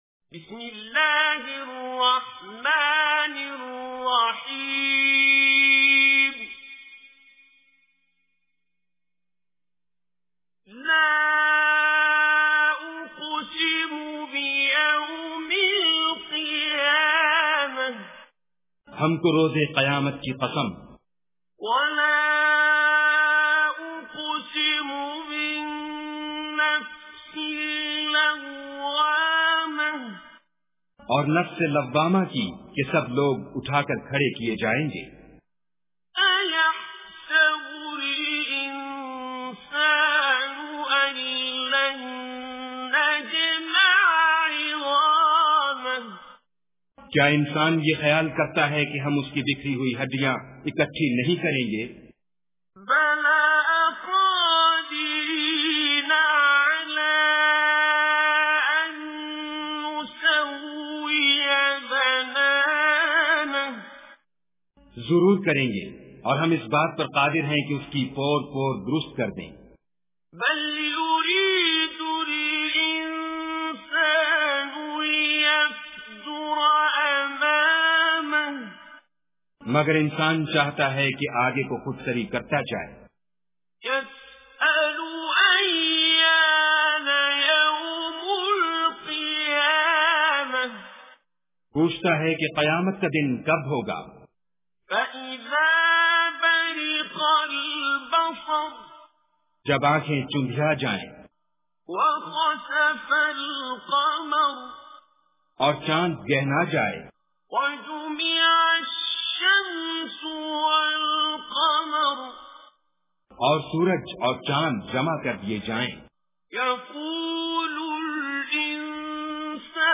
Surah Al-Qiyamah Recitation with Urdu Translation
Surah Al-Qiyamah is 75 chapter of Holy Quran. Listen online and download mp3 tilawat / recitation of Surah Al-Qiyamah in the beautiful voice of Qari Abdul Basit As Samad.
surah-qiyamah.mp3